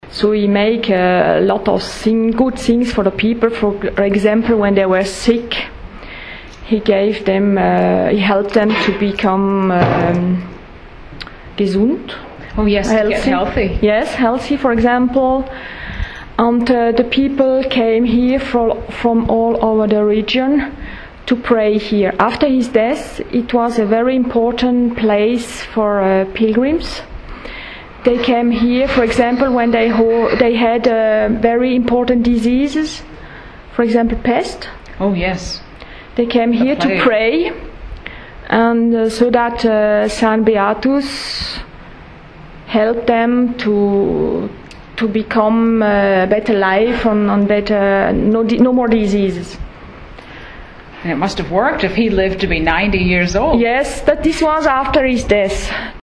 Trickling water in the St Beatus caves